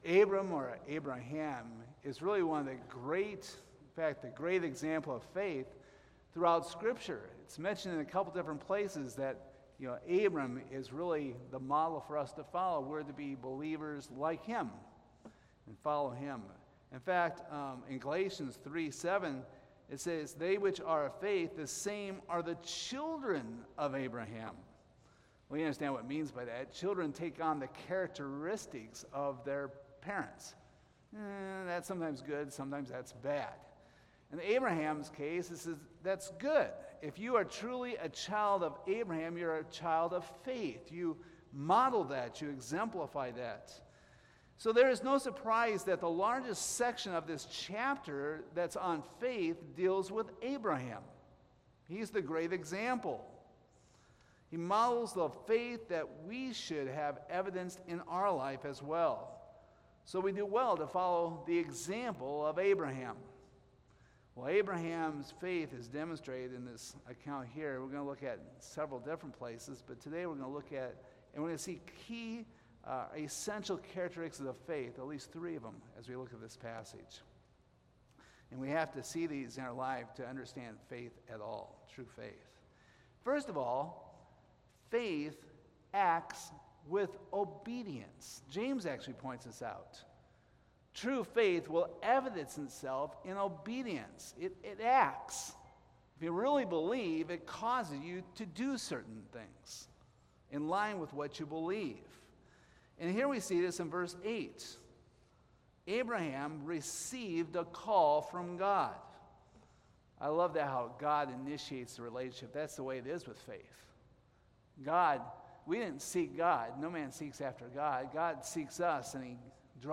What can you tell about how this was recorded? Hebrews 11:8-10 Service Type: Sunday Morning "When God speaks